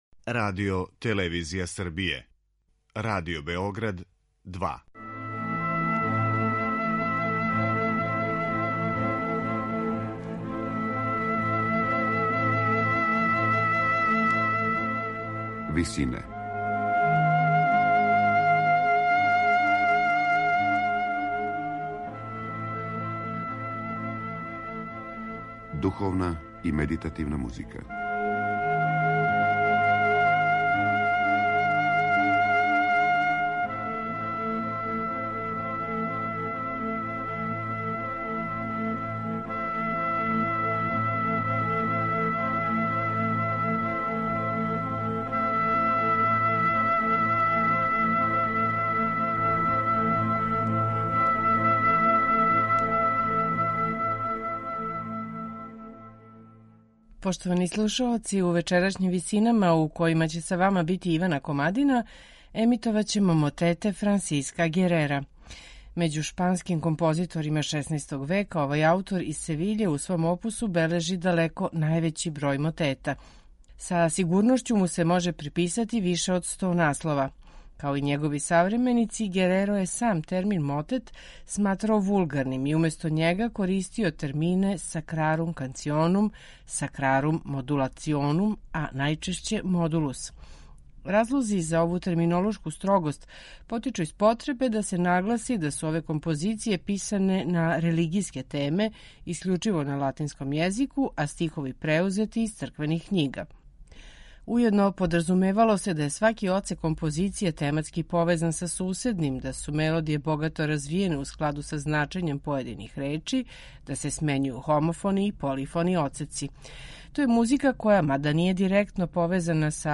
Франсиско Гереро: Мотети
медитативне и духовне композиције